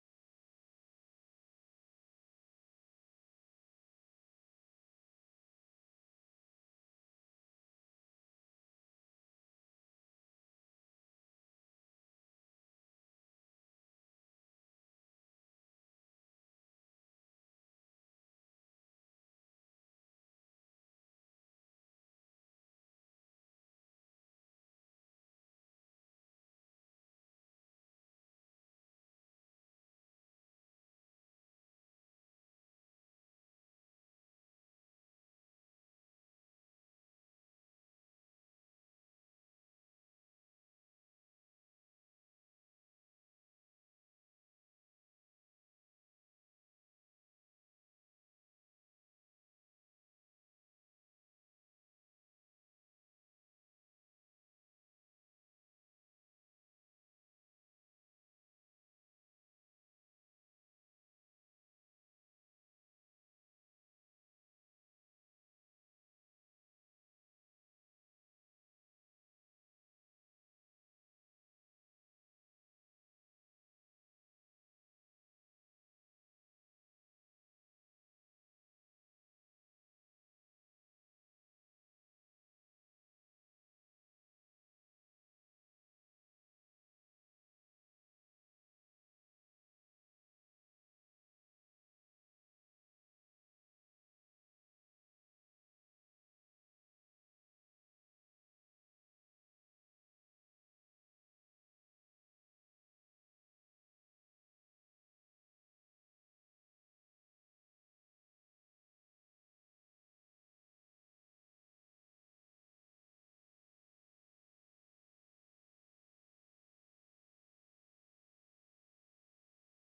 Praise Worship